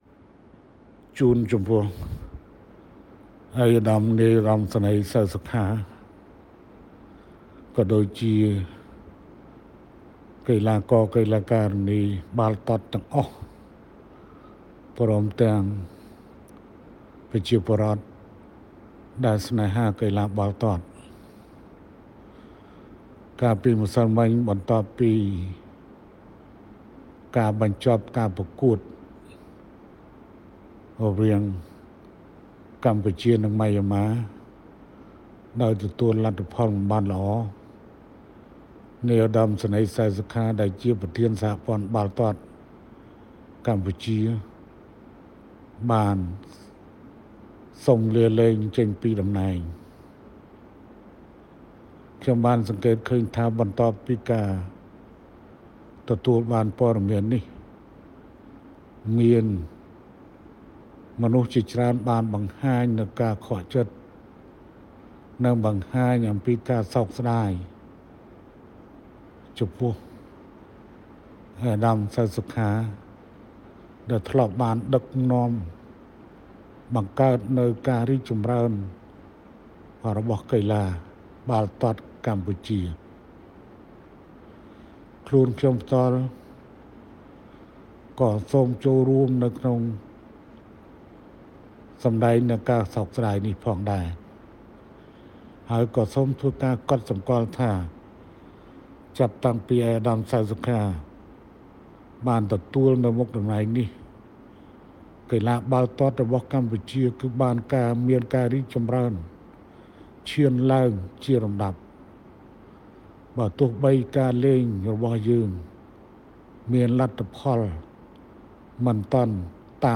ខាងក្រោមនេះជាប្រសាសន៍ទាំងស្រុងរបស់សម្ដេចតេជោ៖